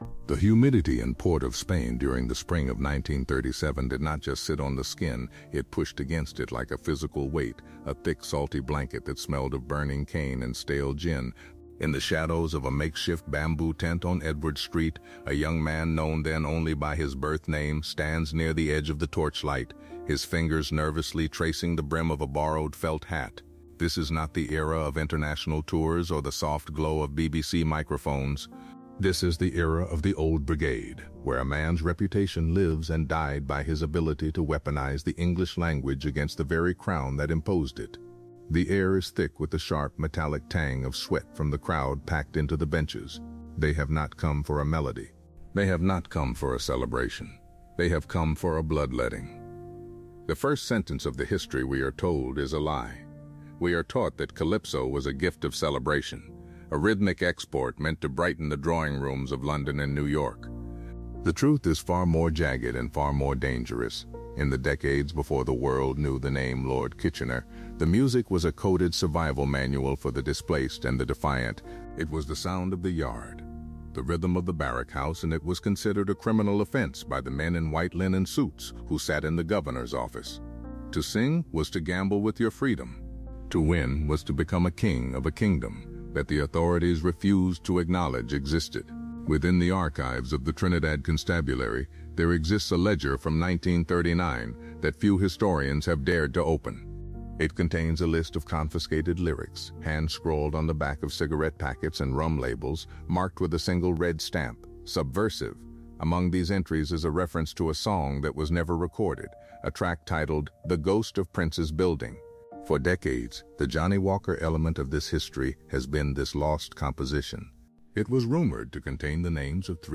In this hardline documentary, we strip away the velvet suits of the 1950s to reveal the gritty, dangerous origins of Lord Kitchener and the "Old Brigade."